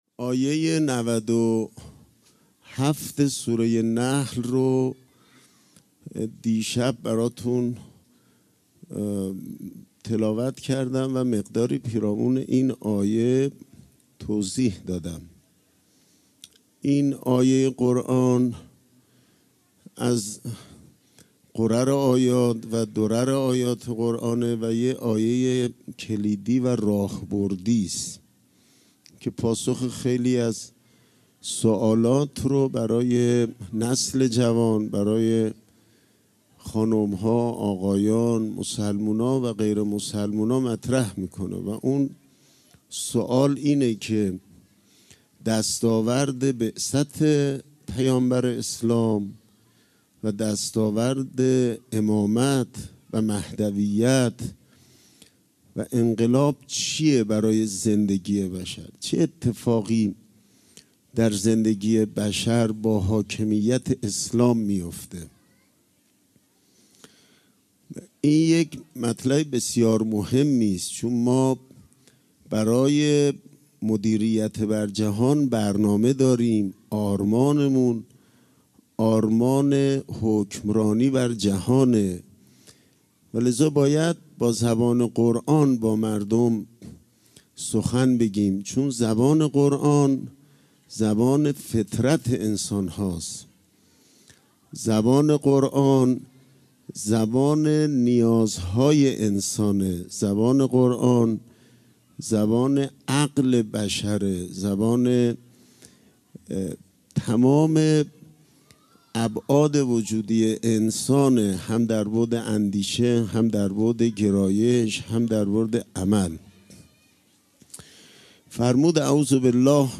صوت مراسم ولادت امام سجاد(ع)
سخنرانی